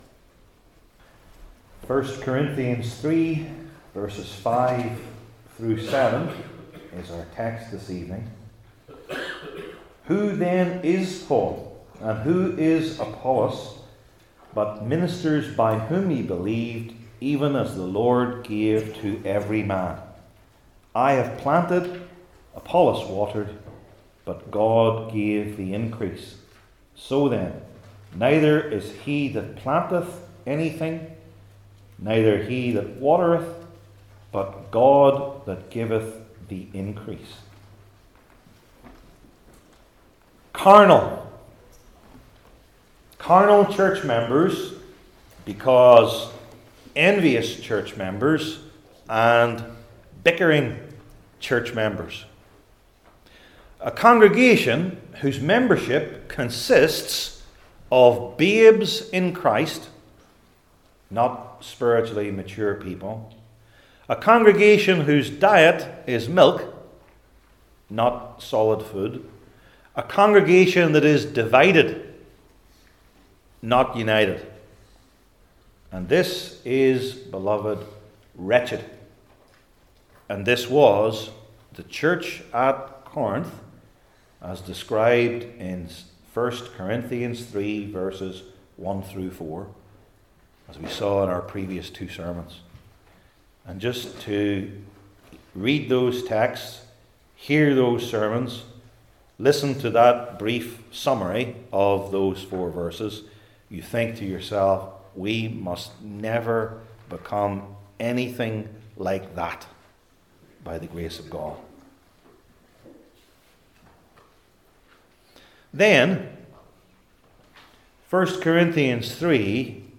I Corinthians 3:5-7 Service Type: New Testament Sermon Series I. The Wrong Views II.